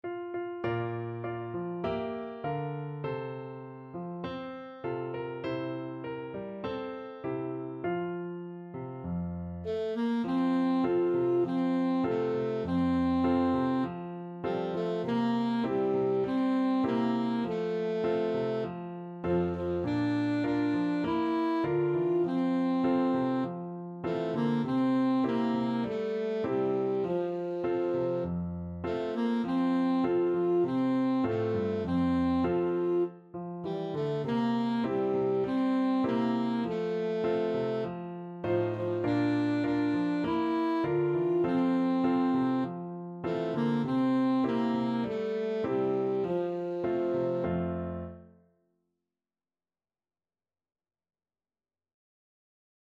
Alto Saxophone
4/4 (View more 4/4 Music)
Moderato
Traditional (View more Traditional Saxophone Music)